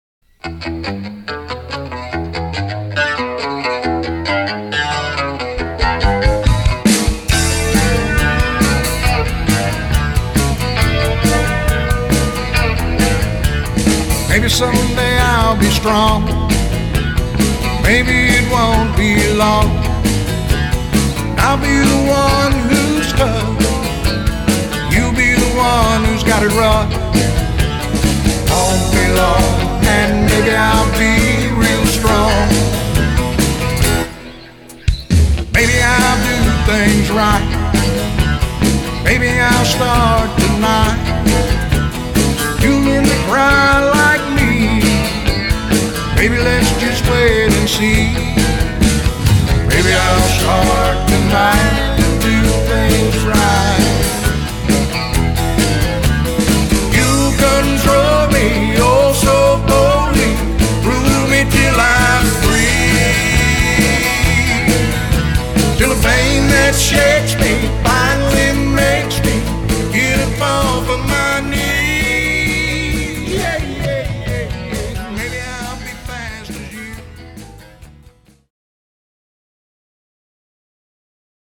A line dancing song if I ever heard one.